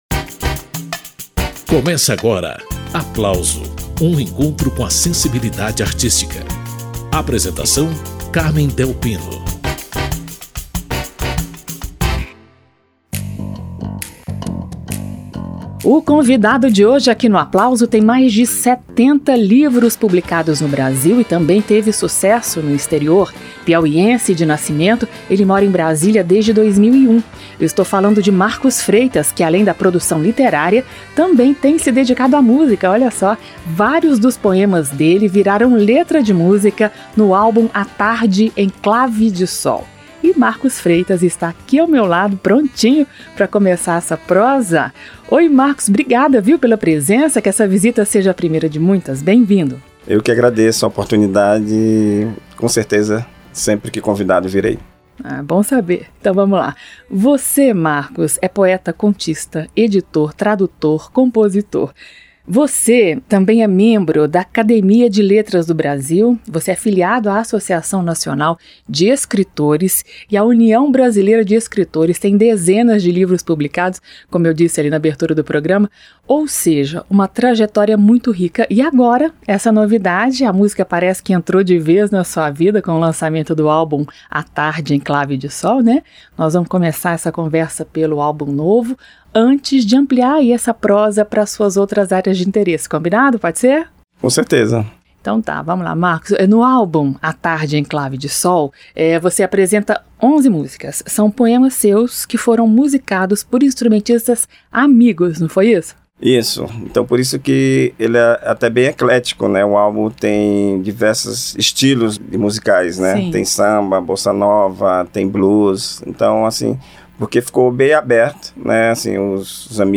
Esta edição do programa Aplauso está regado a música e a poesia.